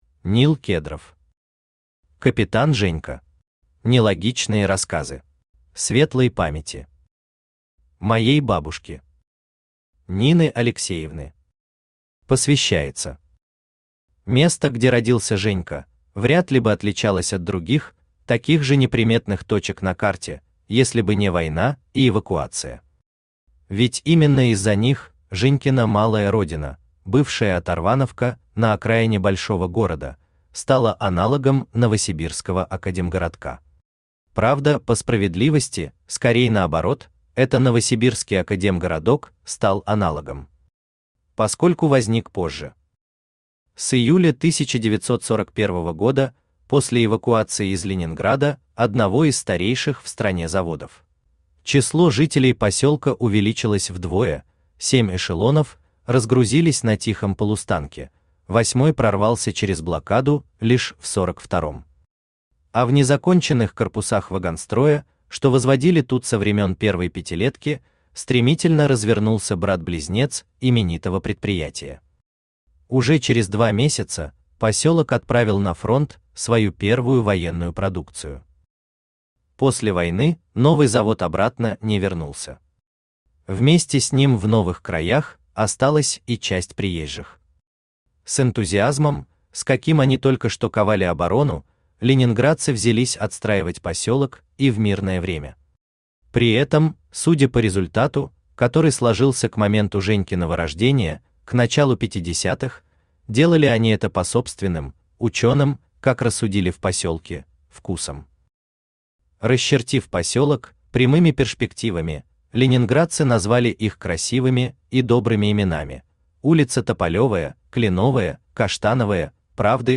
Аудиокнига Капитан Женька. Нелогичные рассказы | Библиотека аудиокниг
Aудиокнига Капитан Женька. Нелогичные рассказы Автор Нил Кедров Читает аудиокнигу Авточтец ЛитРес.